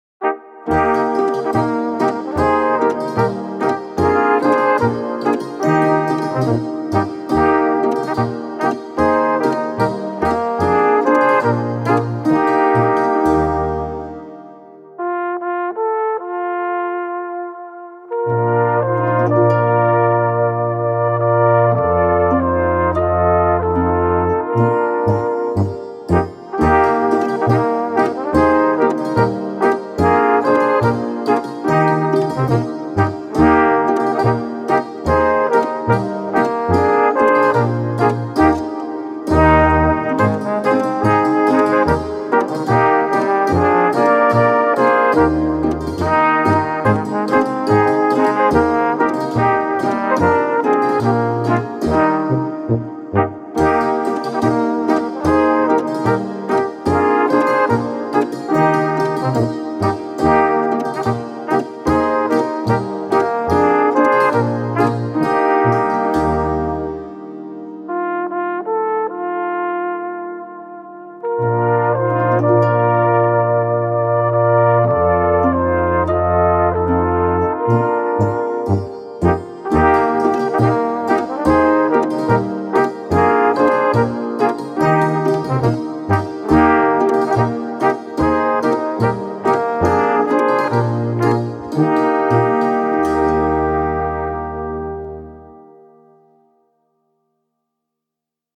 Musikalische Harmonie in vier Stimmen
aus Ramsau im Zillertal (Tuba)
aus Ried im Zillertal (Posaune)
(Flügelhorn)